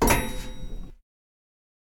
lift.ogg